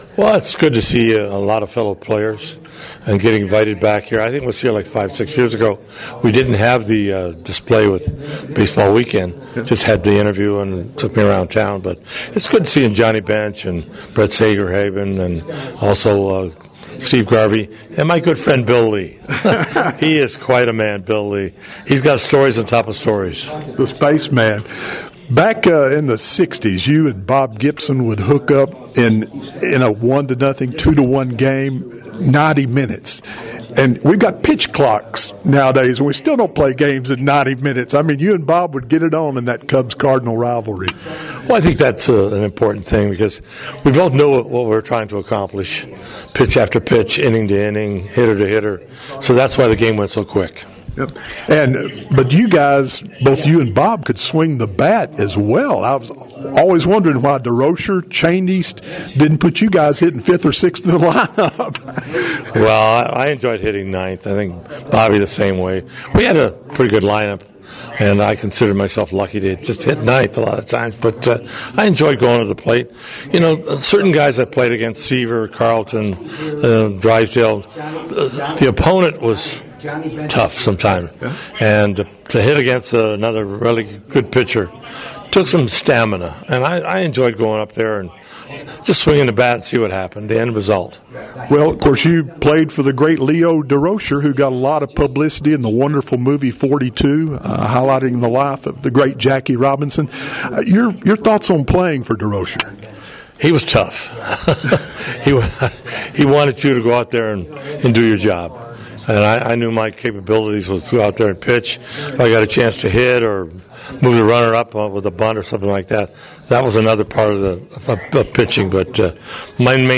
Hall of famers, baseball legends draw fans to Hot Springs for annual baseball weekend